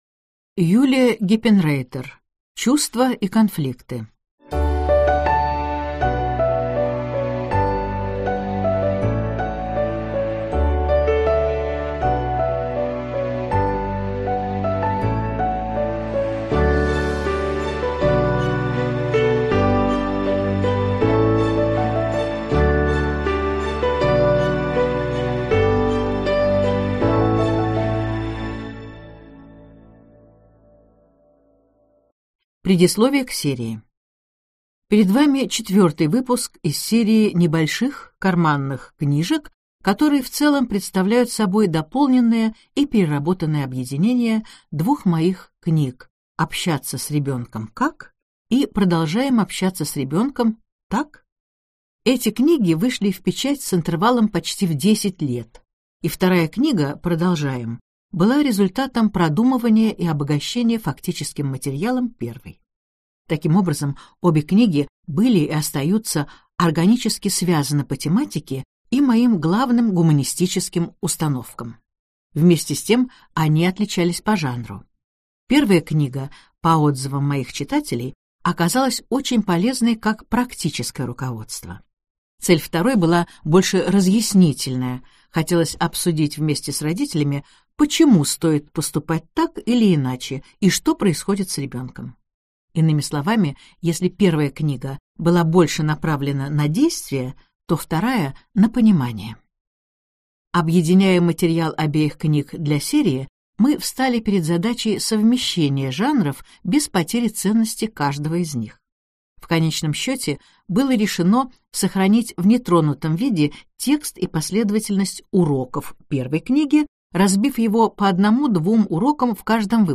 Аудиокнига Чувства и конфликты | Библиотека аудиокниг